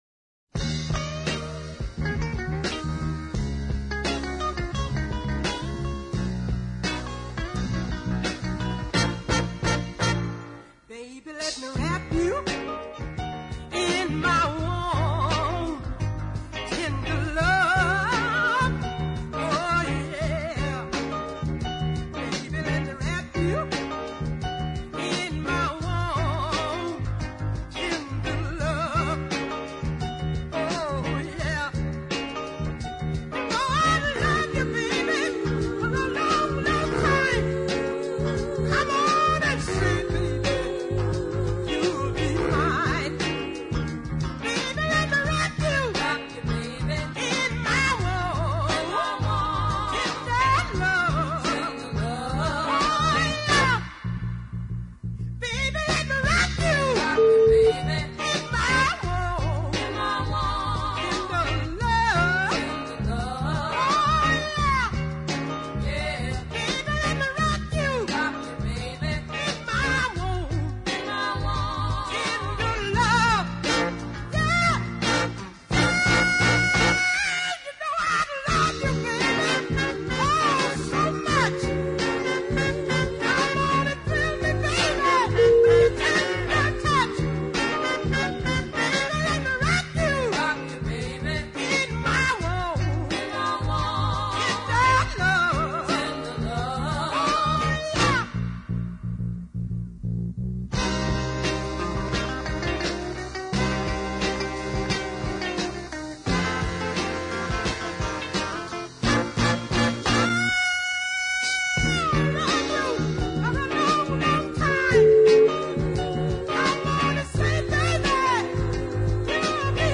a high octane bluesy version